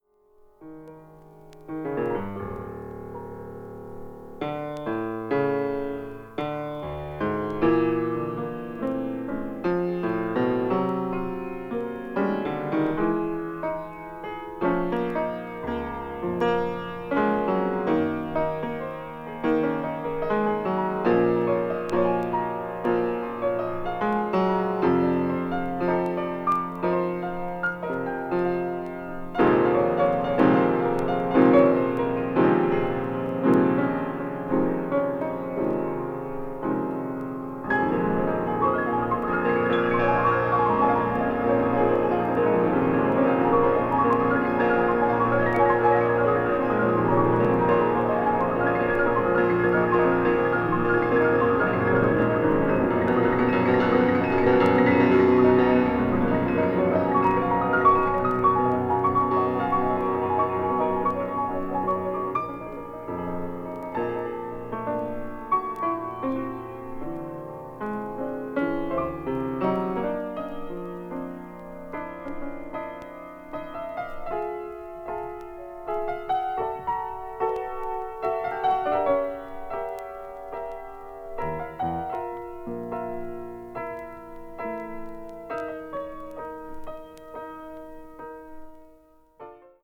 どこか日本的な風情のメロディから幕を開け、とときに煌びやかに美しく、ときに緊張感のある旋律の応酬に音場を彩っていく